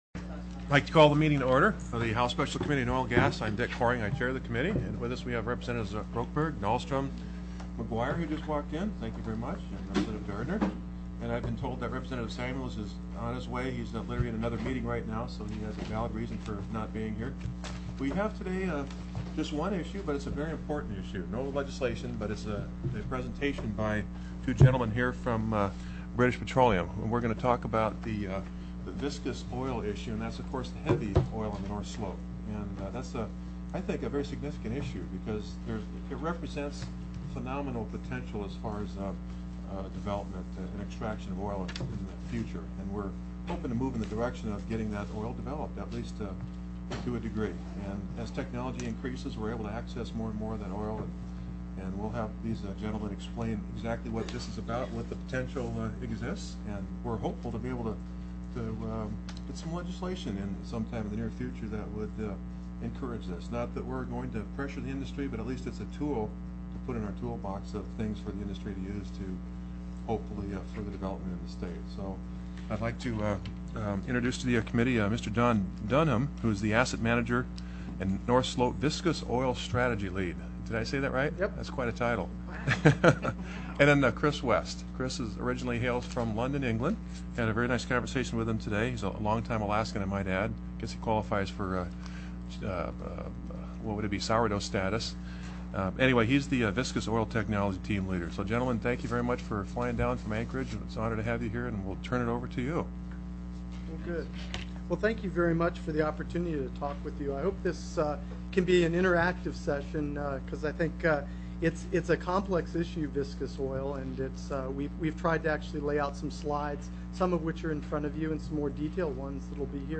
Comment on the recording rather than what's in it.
03/03/2005 05:00 PM House OIL & GAS